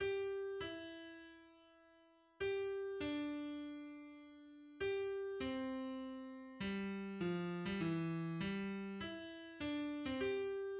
This is the theme in C major just after rehearsal mark 80, played by horns in unison:
\new Staff \relative c' { \clef bass \time 2/2 \key c \major \tempo "Allegro Maestoso."